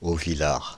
Auvillars (French pronunciation: [ovilaʁ]
Fr-Auvillars.ogg.mp3